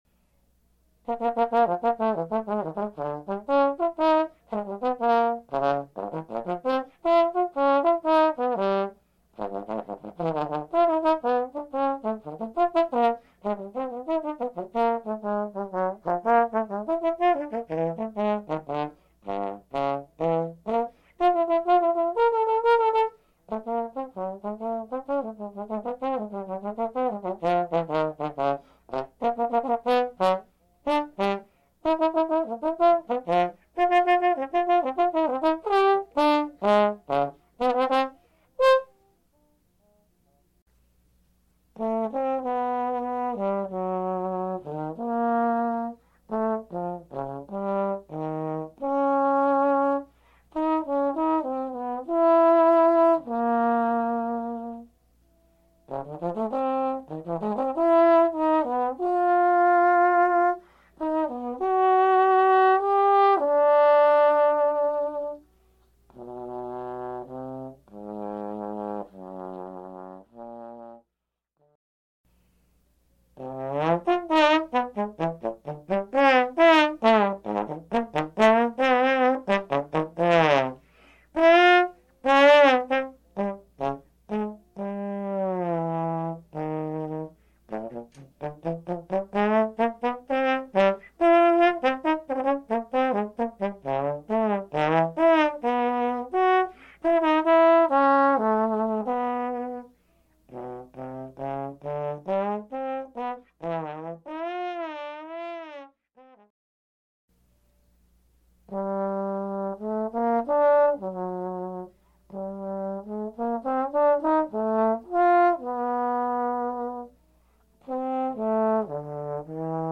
For Trombone Solo